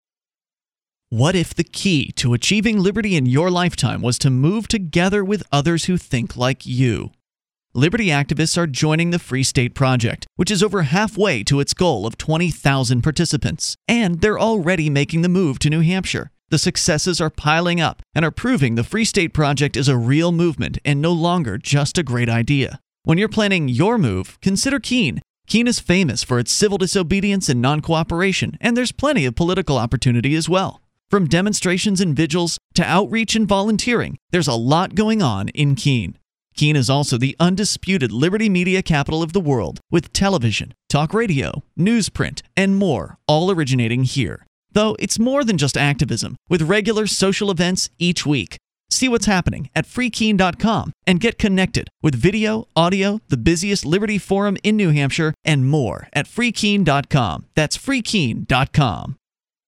FSP/FK :60 Commercial
Here’s a :60 commercial for the Free State Project and Free Keene, but mostly for Free Keene.